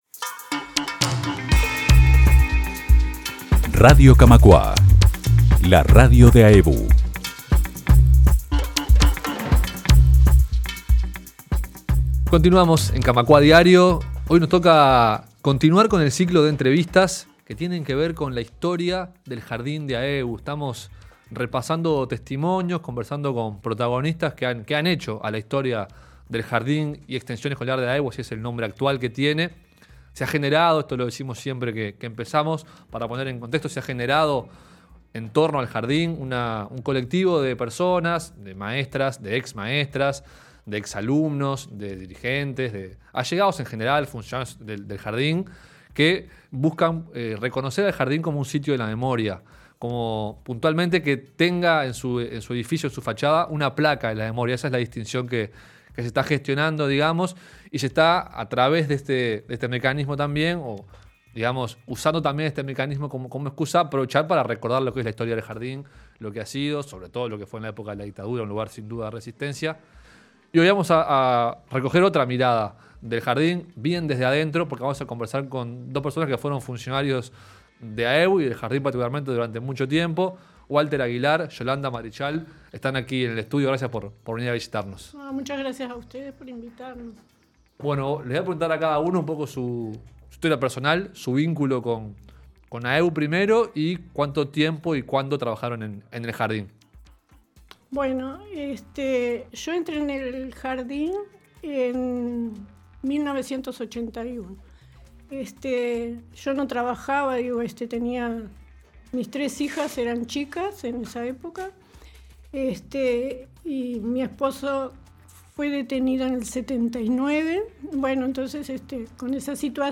En el marco del ciclo de entrevistas sobre la historia del Jardín y Extensión Escolar de AEBU